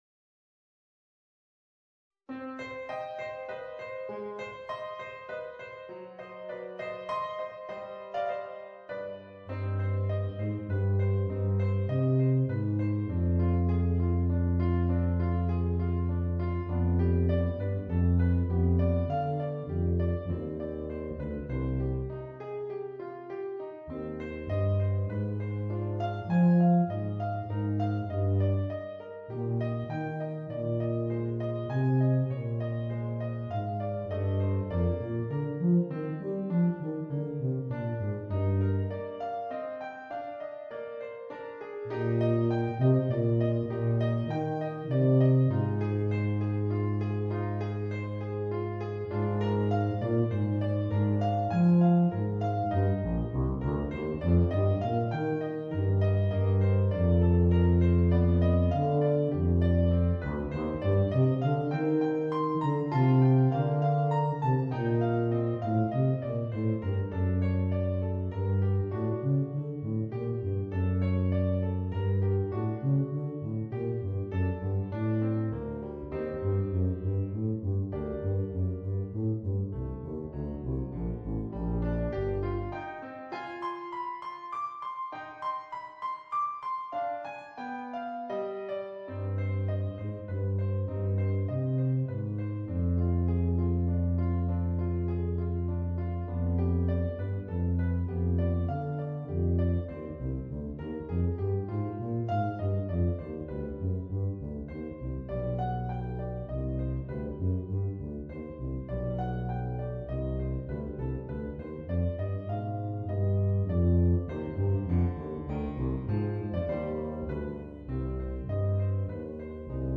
Voicing: Bb Bass and Piano